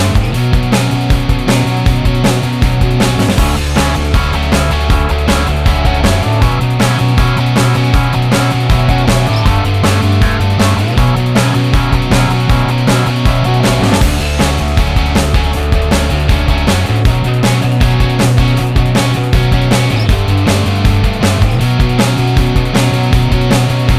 Minus Lead And Solo Rock 4:07 Buy £1.50